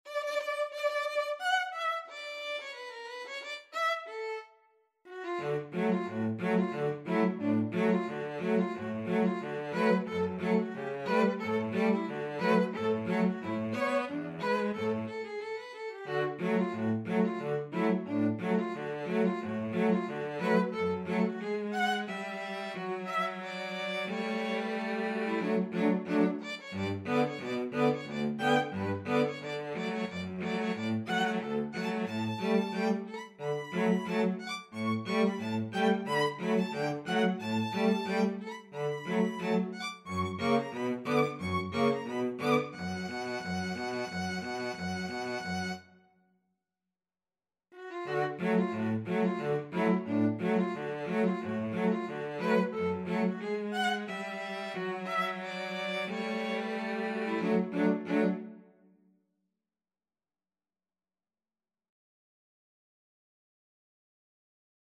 Allegro =180 (View more music marked Allegro)
Classical (View more Classical Violin-Cello Duet Music)